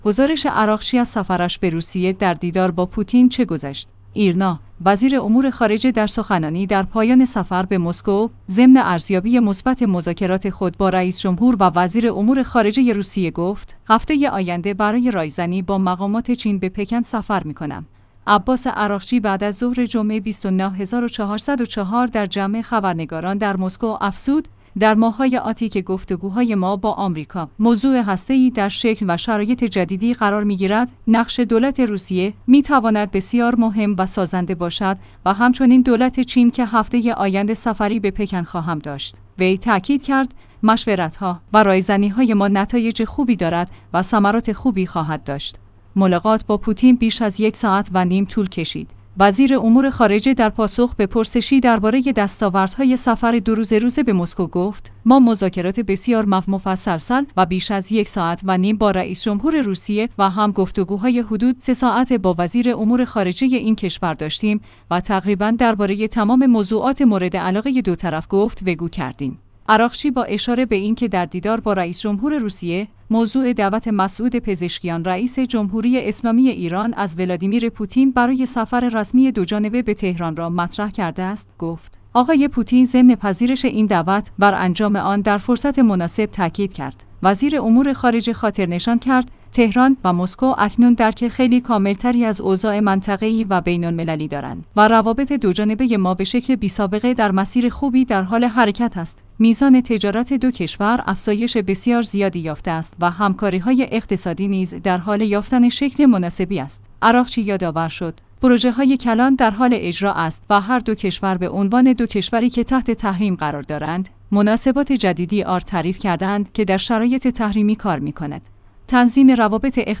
گزارش عراقچی از سفرش به روسیه؛ در دیدار با پوتین چه گذشت؟